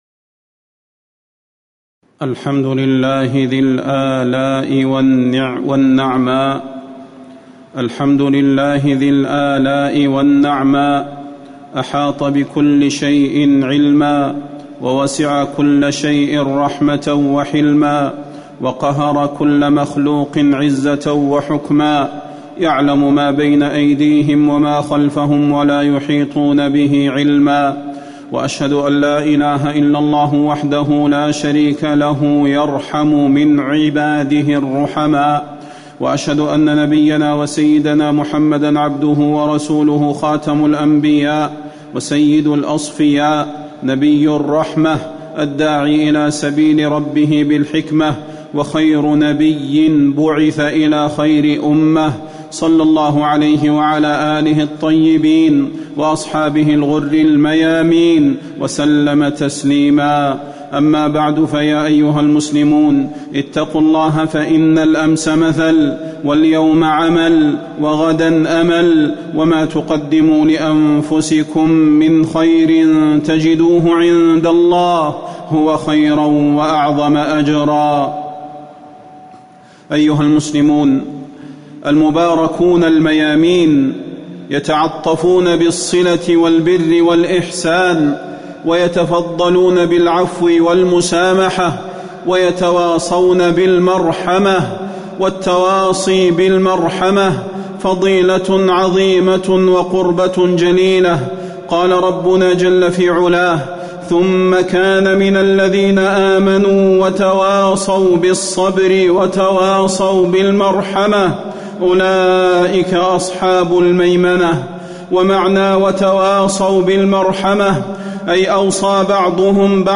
تاريخ النشر ١ ربيع الأول ١٤٤٠ هـ المكان: المسجد النبوي الشيخ: فضيلة الشيخ د. صلاح بن محمد البدير فضيلة الشيخ د. صلاح بن محمد البدير التواصي بالرحمة